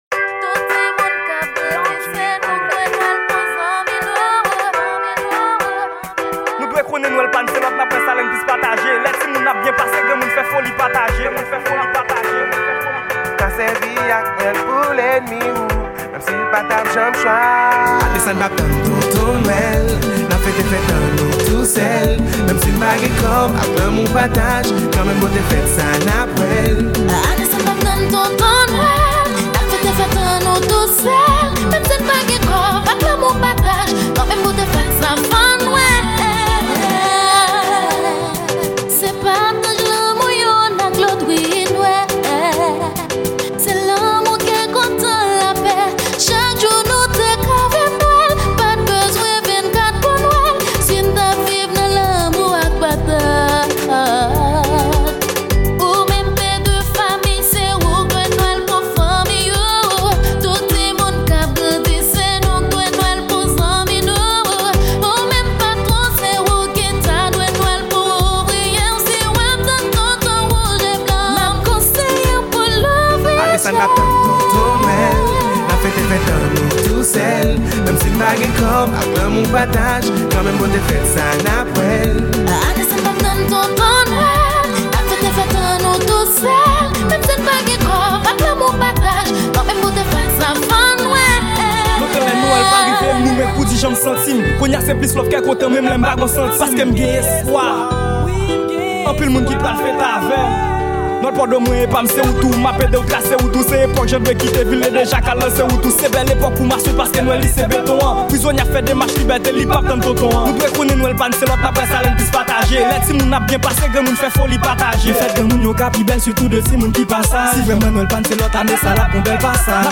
Genre : TAP